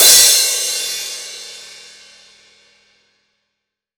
Index of /90_sSampleCDs/AKAI S-Series CD-ROM Sound Library VOL-3/16-17 CRASH